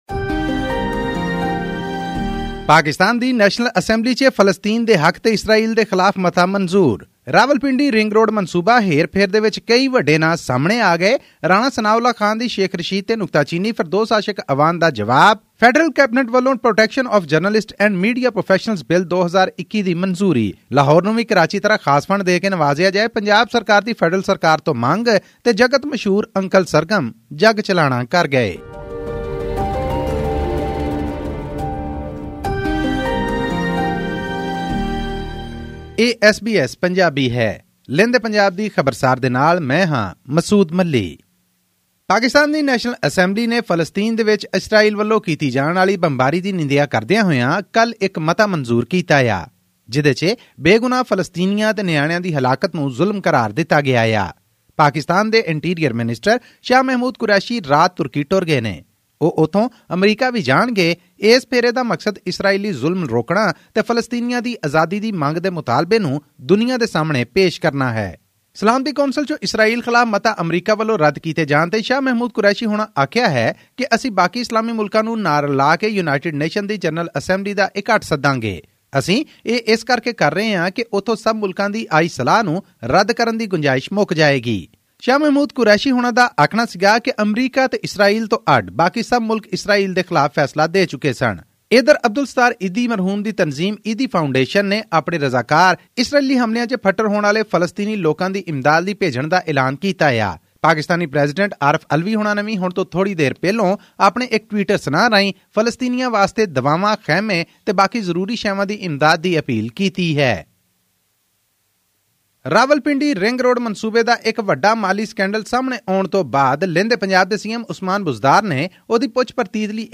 The National Assembly of Pakistan on 17 May passed a resolution denouncing Israel’s "systematic oppression" of the people of Palestine and demanded a UN investigation for the "human rights violations." All this and more in our weekly news bulletin from Pakistan.